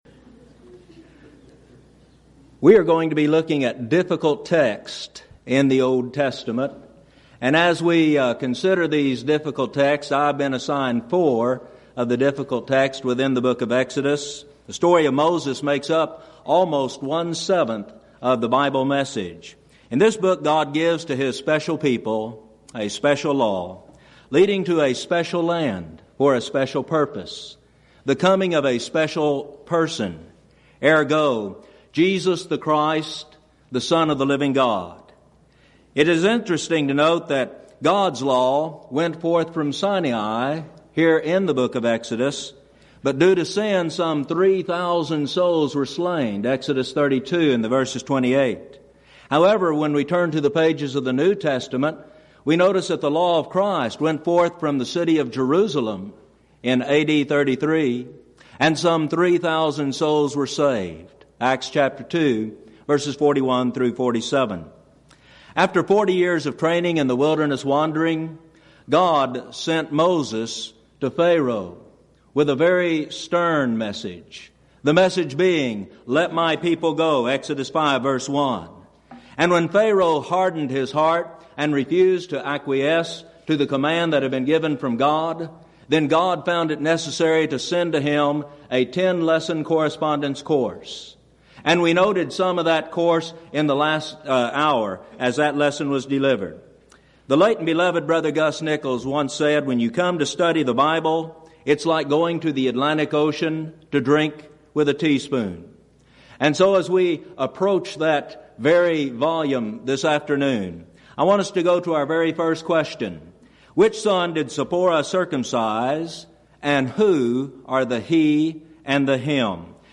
Event: 2nd Annual Schertz Lectures Theme/Title: Studies In Exodus